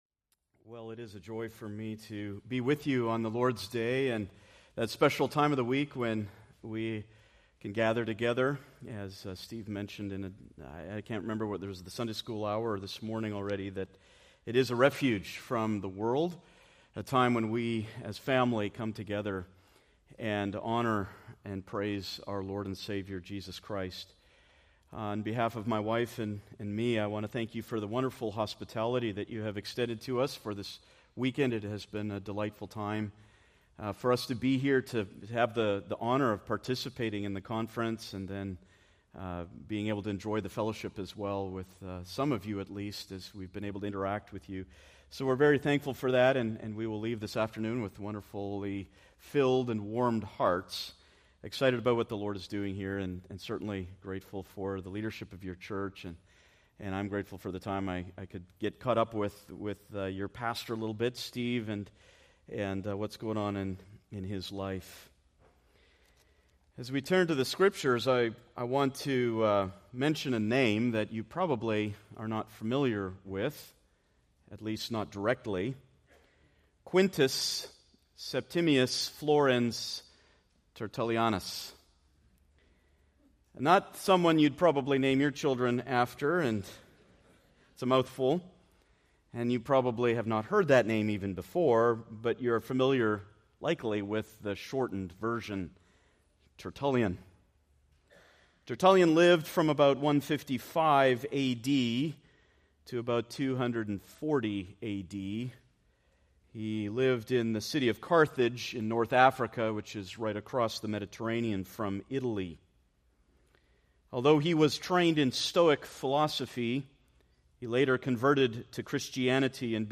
Preached September 29, 2024 from Colossians 2:8